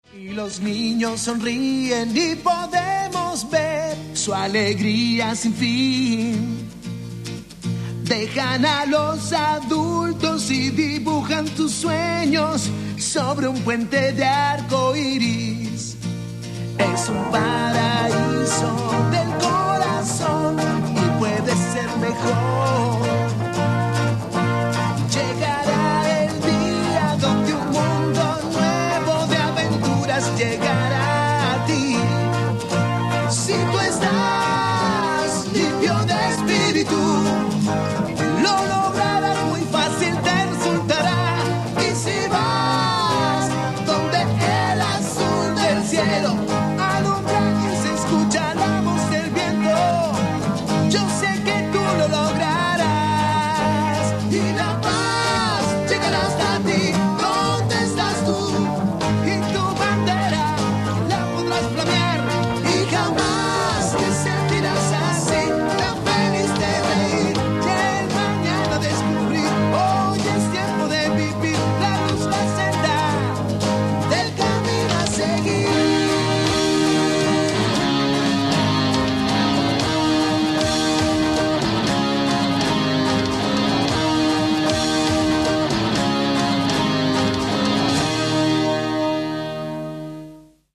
Tema de apertura